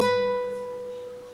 guitare2.wav